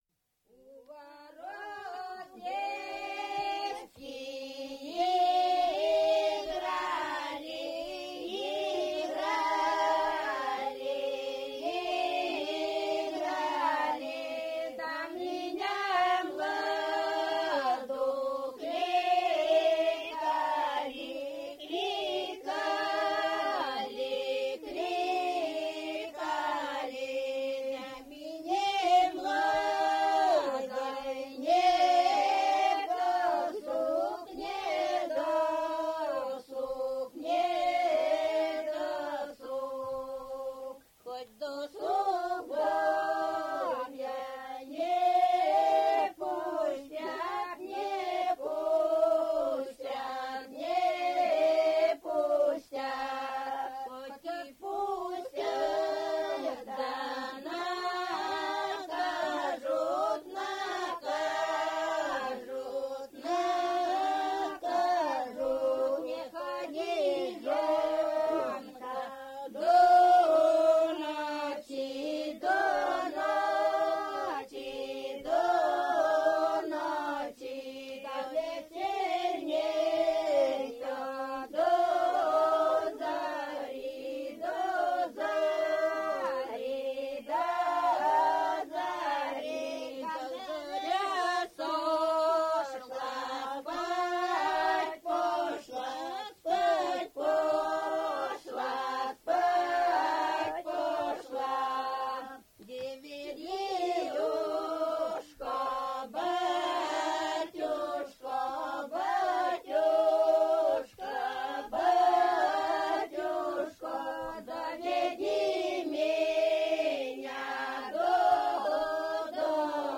Важной чертой напевов вечёрочных хороводов является декламационный характер соотношения слова и напева.
Большинство песенных образцов записано в ансамблевой форме исполнения.
01 Летняя круговая песня «У ворот девки играли» в исполнении жительниц с. Карсовай Балезинского р-на Удмуртской Респ.